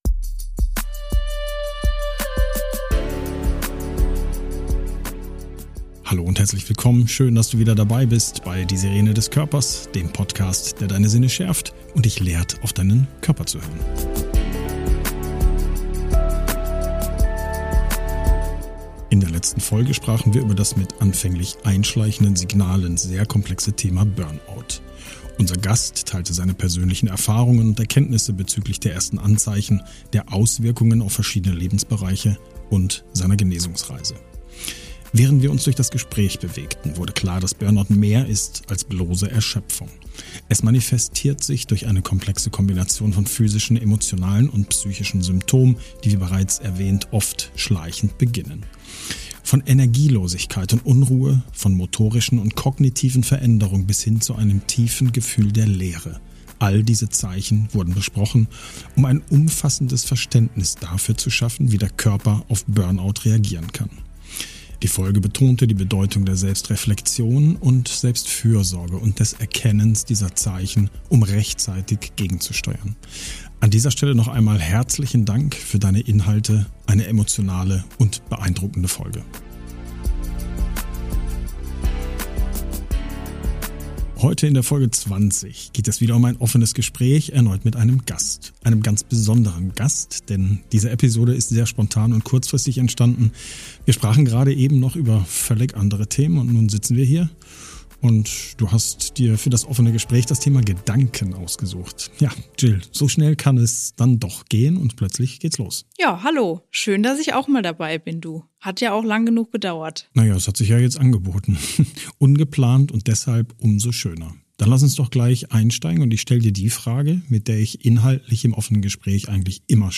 Gemeinsam mit einem Gast habe ich ein offenes Gespräch, in dem wir unsere Ansichten zu verschiedenen Fragen teilen. Wir diskutieren über Selbstzweifel, Momente kreativer Eingebungen, die Rolle der Intuition und das spannende Konzept des Gesetzes der Resonanz. Wir werfen einen Blick darauf, wie Gedanken Ängste auslösen können, aber auch wie sie uns dazu motivieren können, positive Veränderungen herbeizuführen.